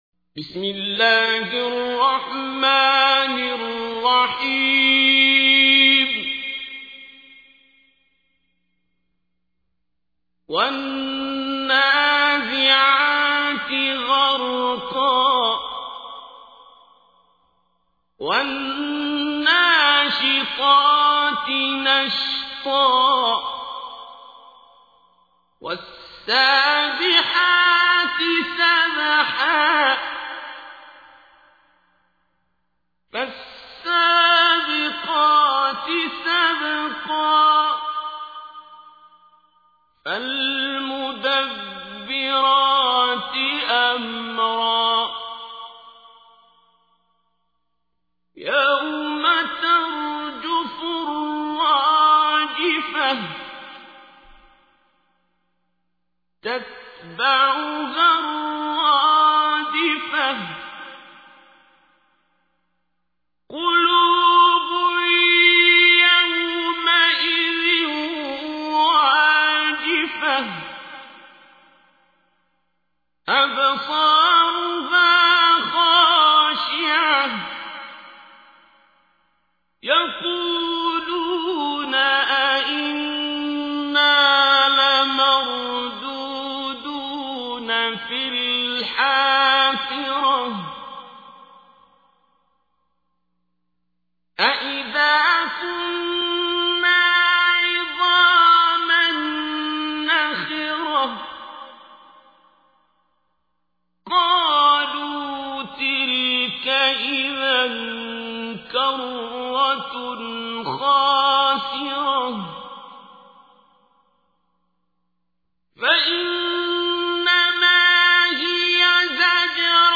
تحميل : 79. سورة النازعات / القارئ عبد الباسط عبد الصمد / القرآن الكريم / موقع يا حسين